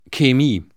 Lautschrift 🔉 [çeˈmiː] süddeutsch, österreichisch: 🔉 [k…] schweizerisch: [x…]